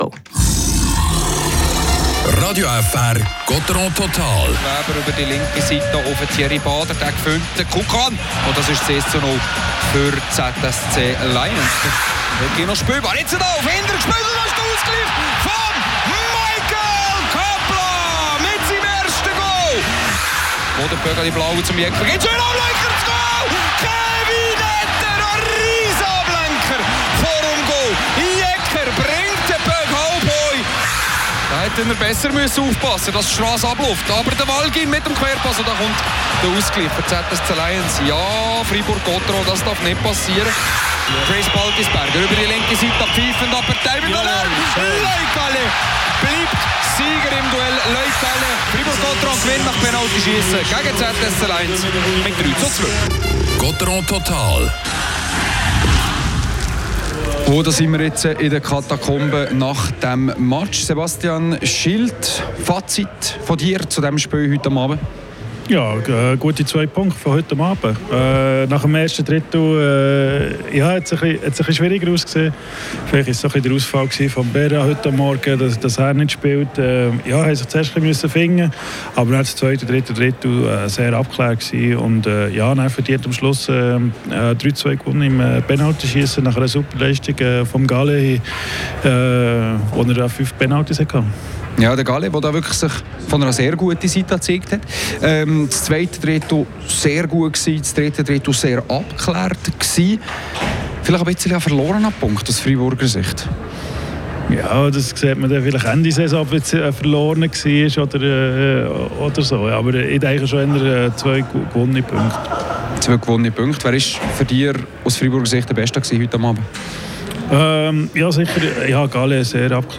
Die Interviews